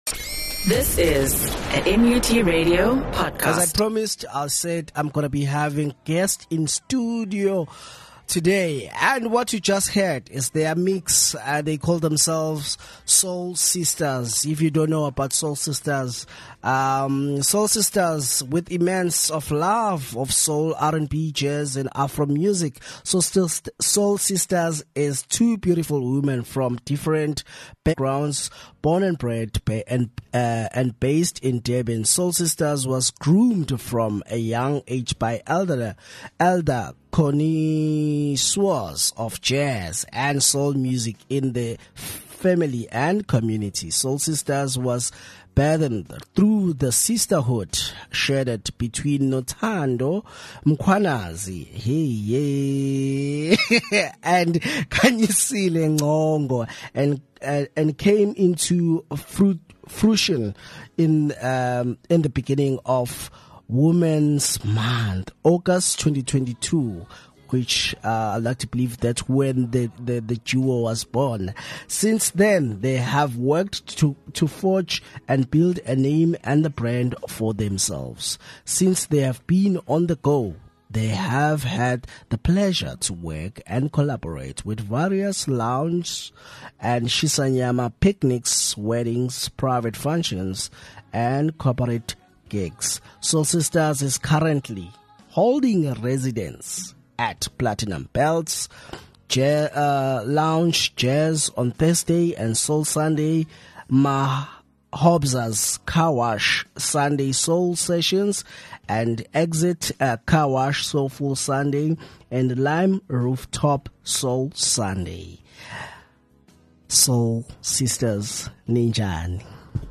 interview
The Soul Sistaz are a dynamic female DJ duo formed during the COVID-19 pandemic. Since their debut, they’ve never looked back — continuously securing bookings and making their mark in the music scene.